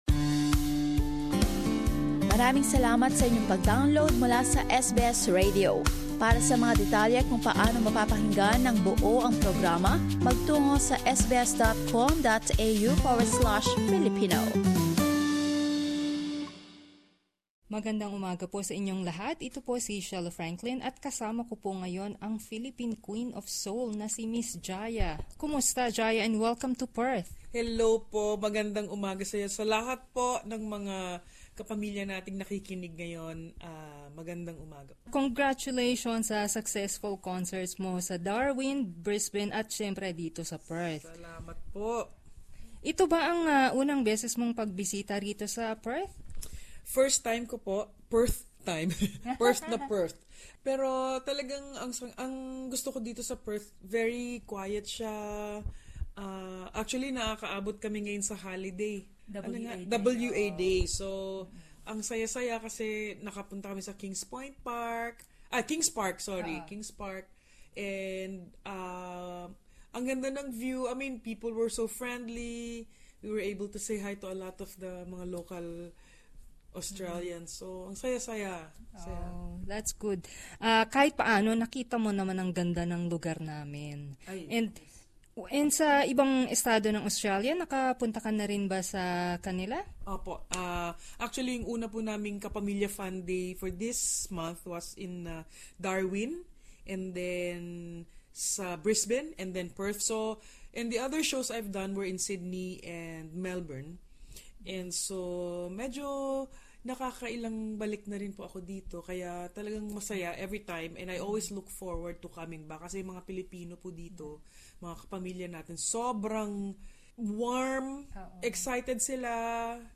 caught up with Jaya after her first concert in Perth.